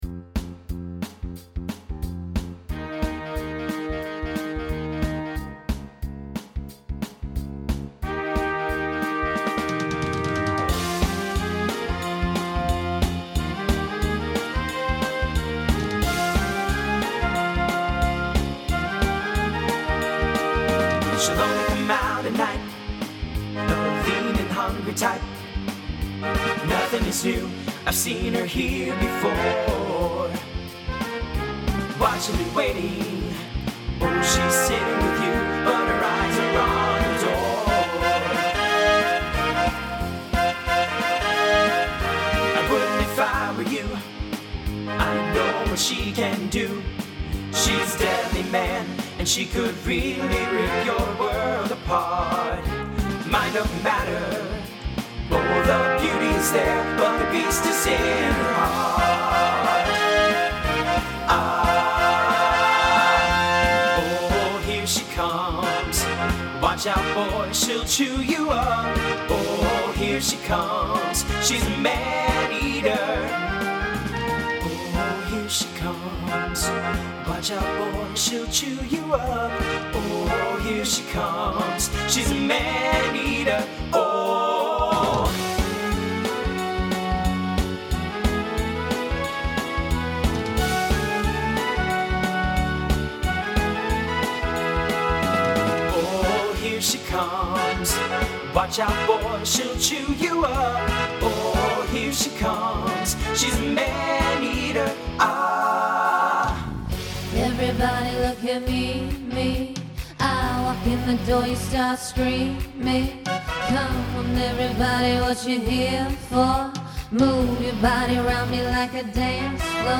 TTB/SSA
Instrumental combo
Pop/Dance